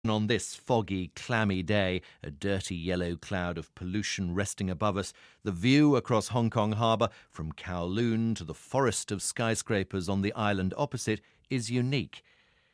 【英音模仿秀】到乡翻似烂柯人 听力文件下载—在线英语听力室